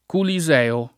Culiseo [ kuli @$ o ]